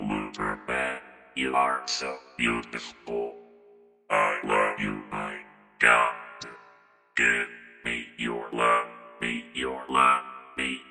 机器人的声音剪辑 " 机器人的声音你说了什么？
描述：这是通过声码器乐器以大胆的方式制作的。
标签： 机器人 语音 语音编码器
声道立体声